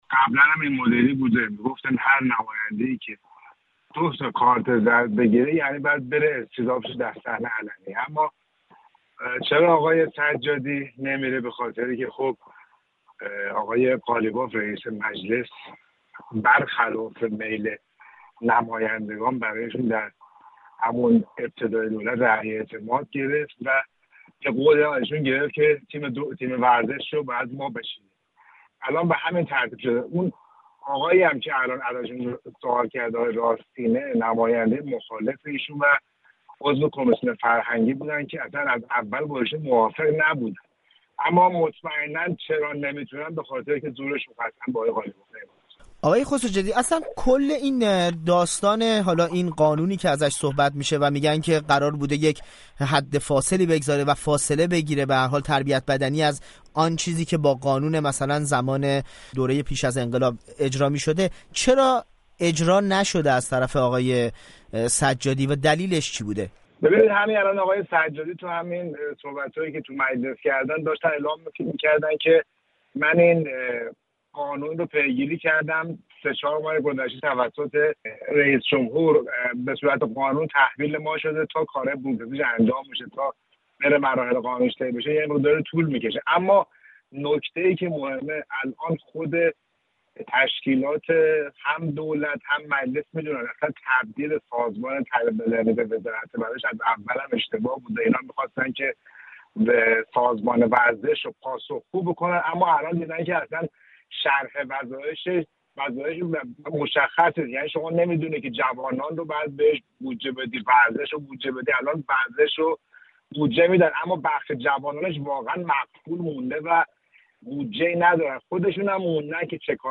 خبرنگار ورزشی در لندن